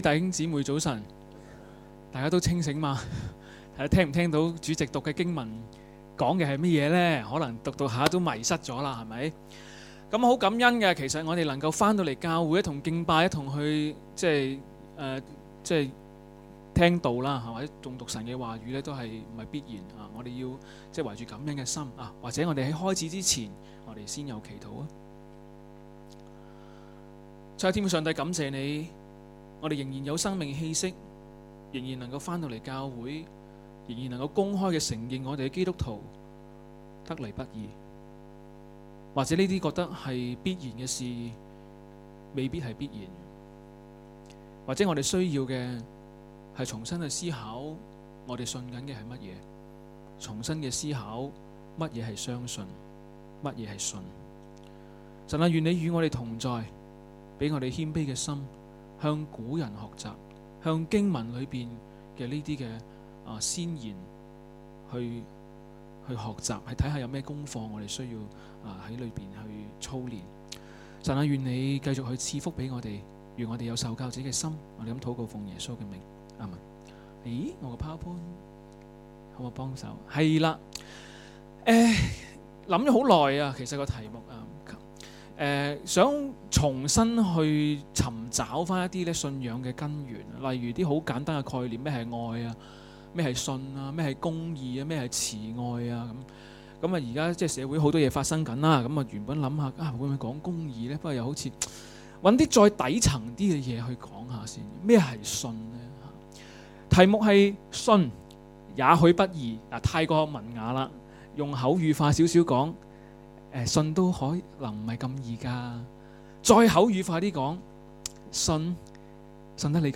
2019年8月24日及25日崇拜